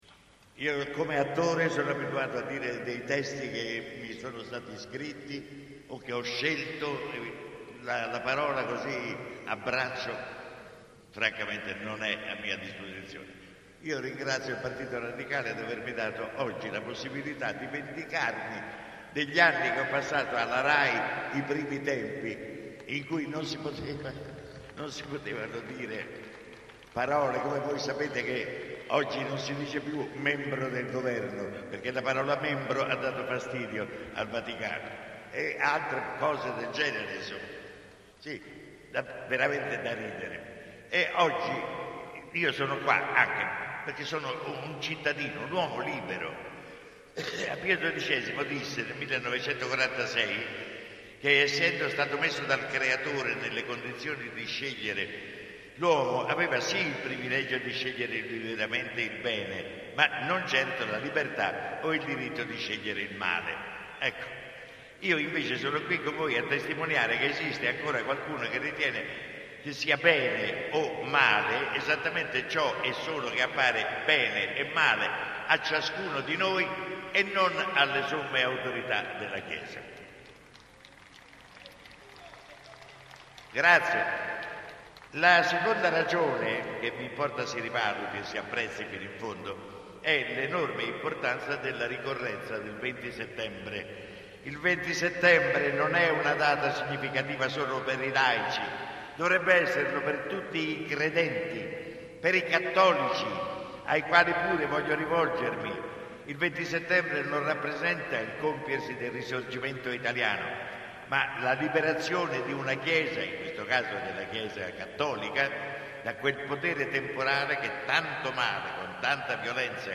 Arnoldo Foà: audio manifestazione Radicale a Porta Pia del 2000 (Agenzia Radicale Video)